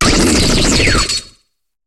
Cri de Lucanon dans Pokémon HOME.